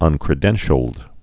(ŭnkrĭ-dĕnshəld)